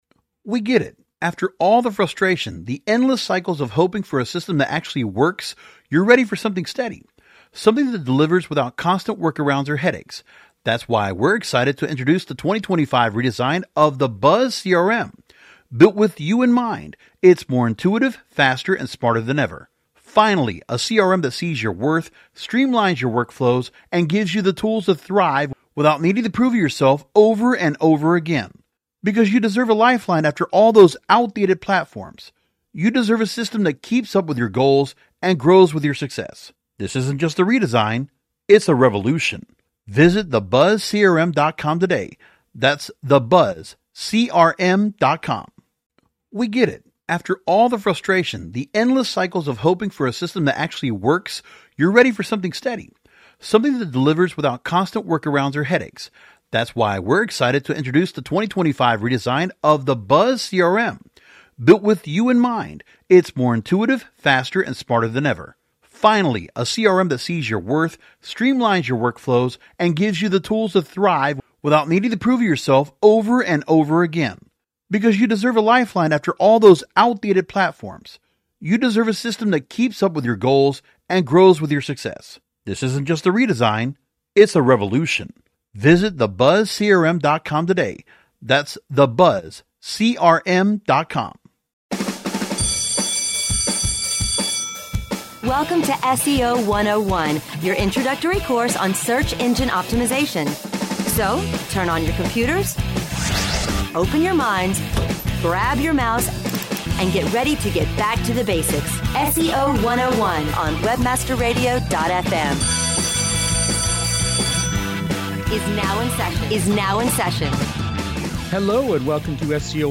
This episode delves into the negatives of some significant changes to the Google Search Console (in Beta), and Google My Business’s new Agency Dashboard rife with bugs. The two SEO geeks also discuss the mind-boggling 6 million manual actions Google applied to websites last year, a possibly earth-shaking Google patent, questions from listeners, and more.